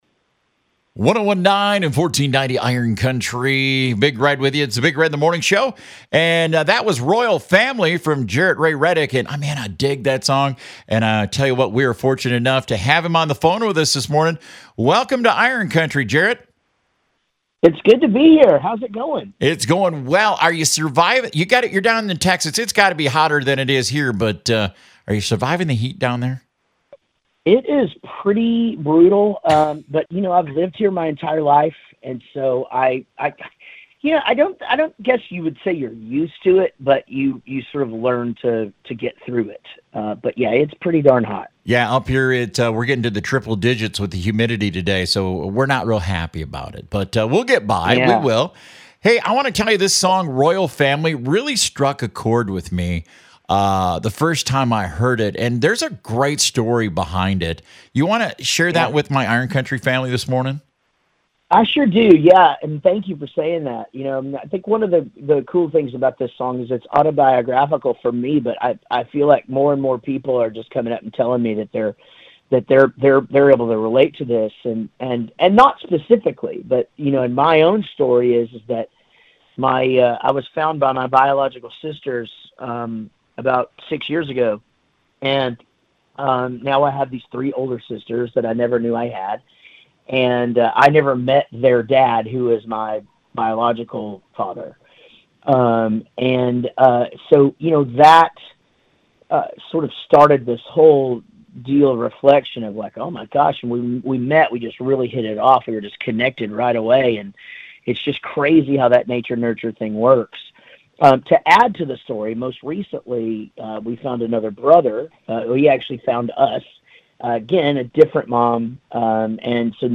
Jaret Ray Reddick Interview
JARET-RAY-REDDICK-INTERVIEW.mp3